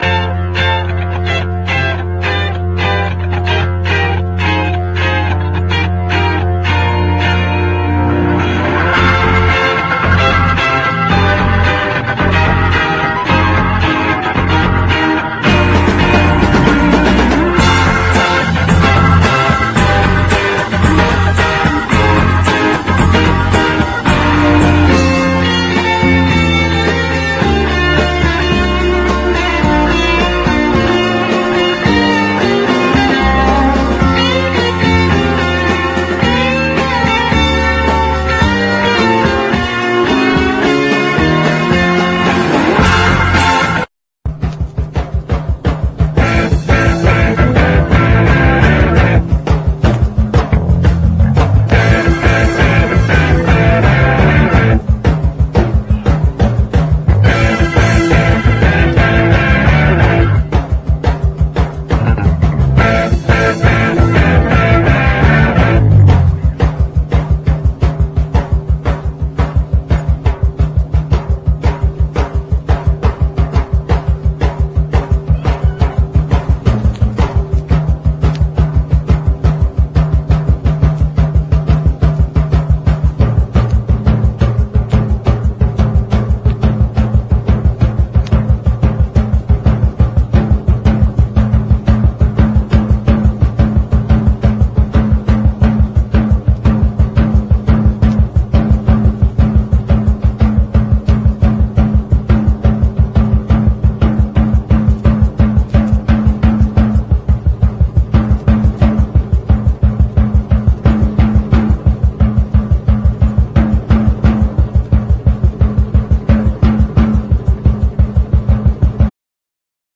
DRUM BREAK
JAZZ FUNK
ヘヴィー・ヒッティングなドラムの強力なグルーヴィー・カヴァー！
ファンキーに打ちまくってます！